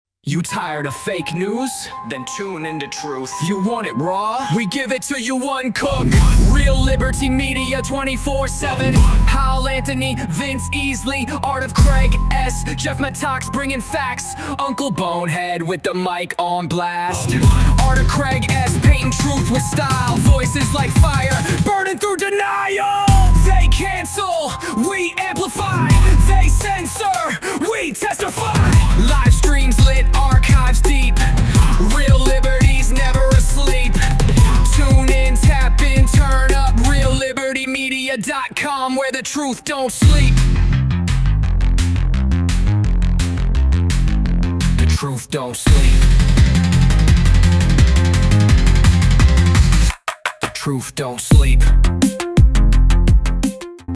Station ID - Bumper - 53 Secs.mp3